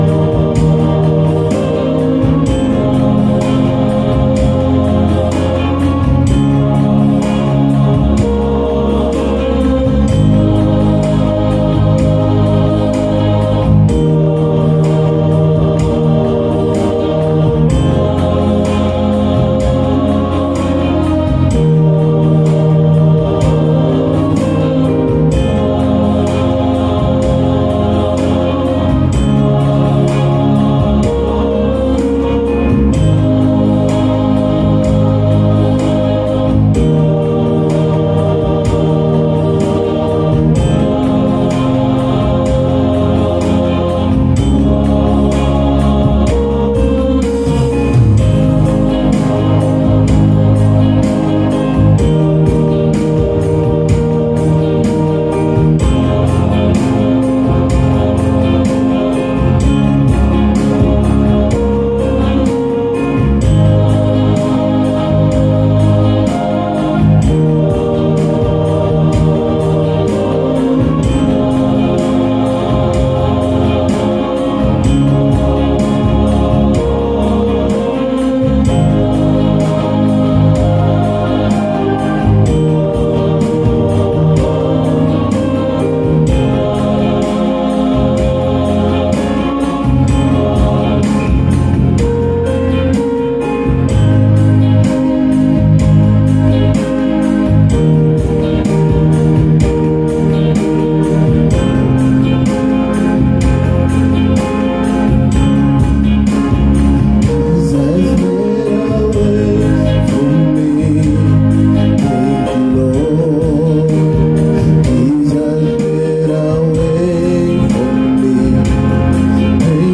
Morning Devotion And Prayer, Proverbs Chapter 1